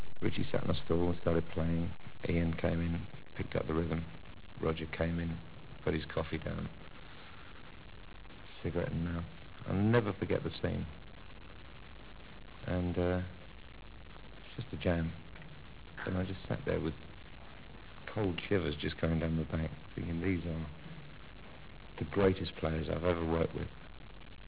THE TOMMY VANCE INTERVIEWS